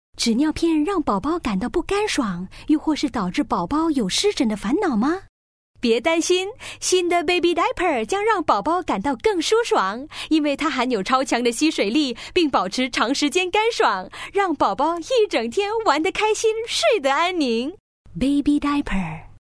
Female
Showreel